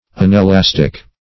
Unelastic \Un`e*las"tic\